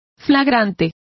Complete with pronunciation of the translation of flagrant.